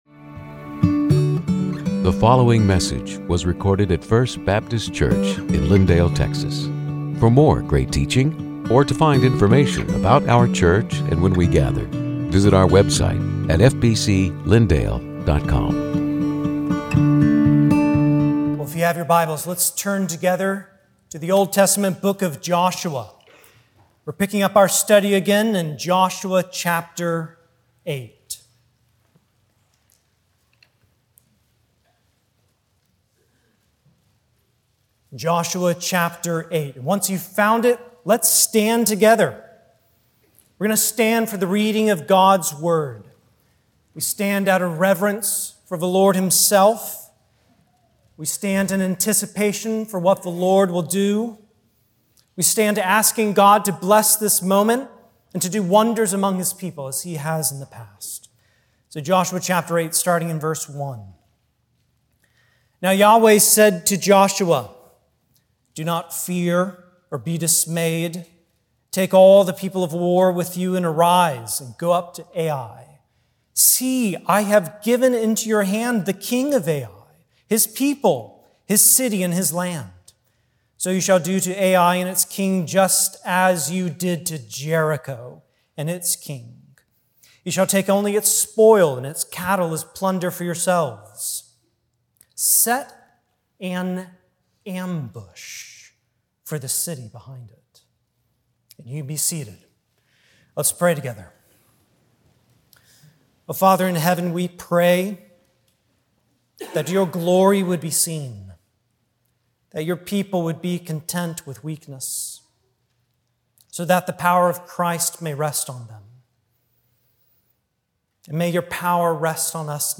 Sermons › Joshua 8:1-35